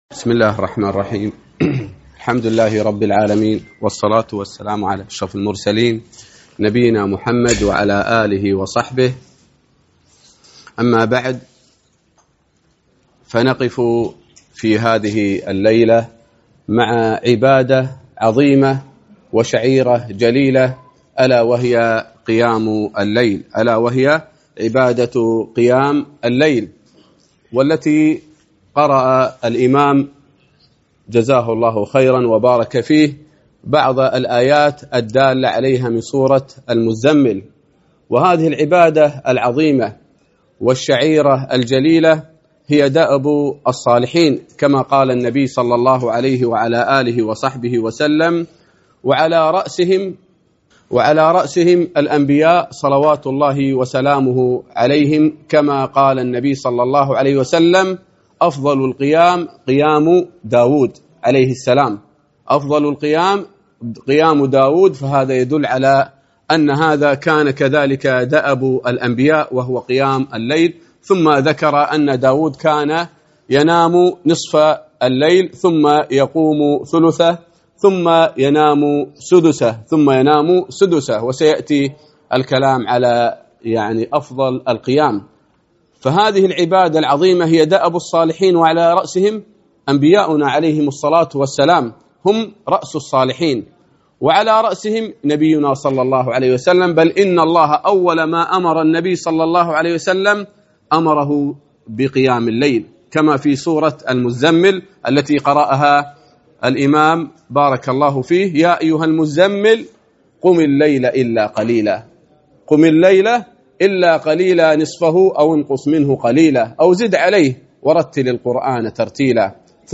محاضرة - قيـام الليــل